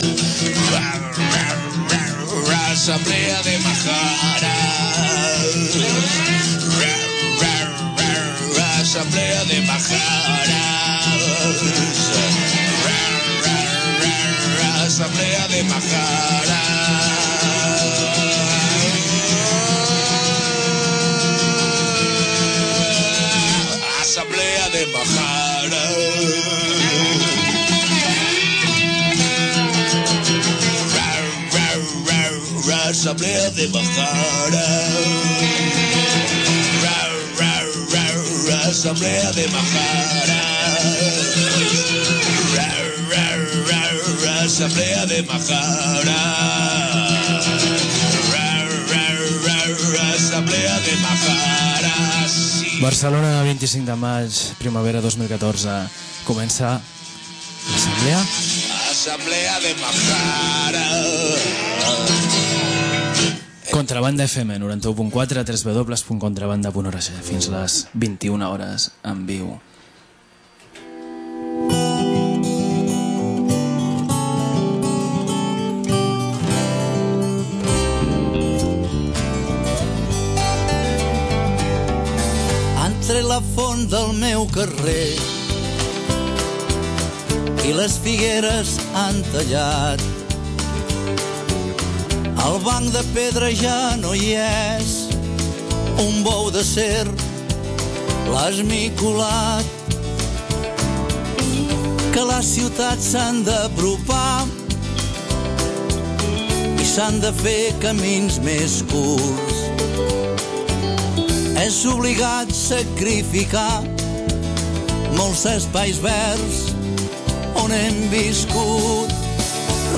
Escoltem àudios que ens envien companyes del centre Women In Solidarity House de Lesbos per explicar-nos la situació de les refugiades en aquesta illa grega després de l’incendi del camp de Moria, i comentem que la campanya de la Caravana Obrim Fronteres organitza convocatòries de denúncia aquí.